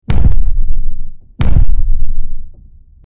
Giant Stomping Footsteps
Giant_stomping_footsteps.mp3